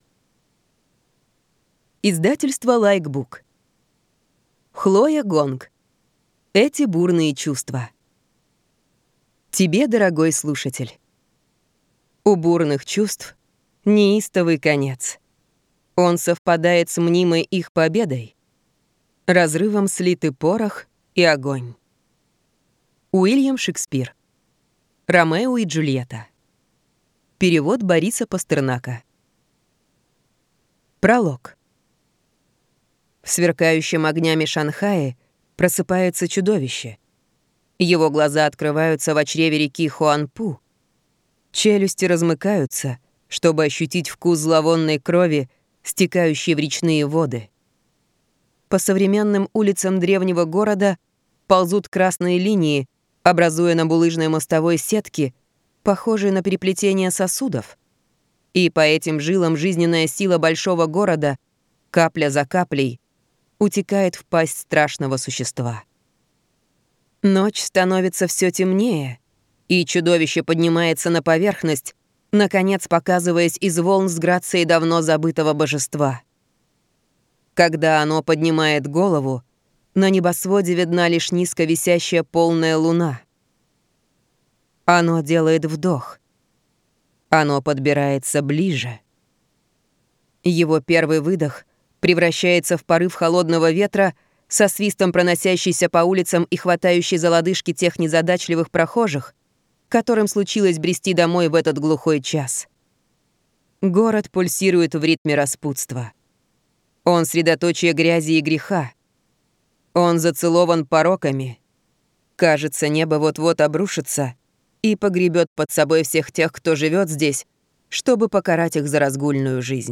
Аудиокнига Эти бурные чувства | Библиотека аудиокниг